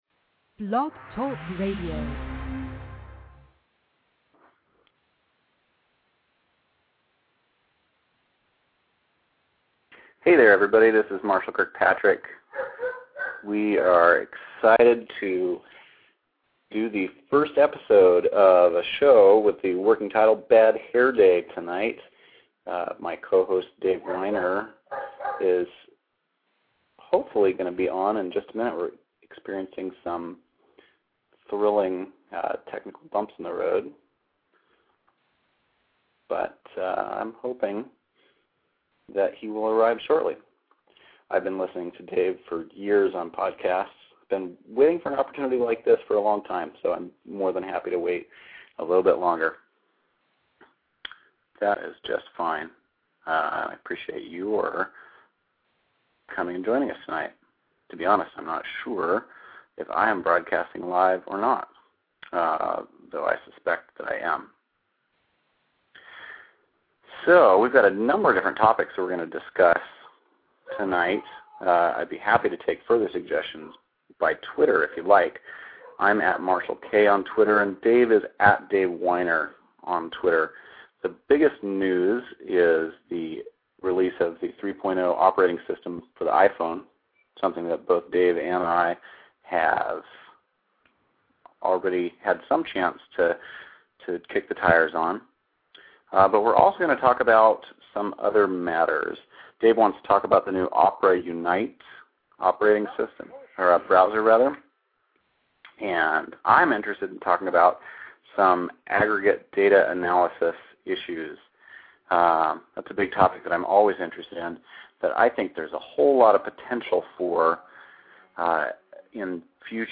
Well, this is the first Bad Hair Day podcast, and the good news is -- it was a pretty good show, a conversation between two techies about: 1.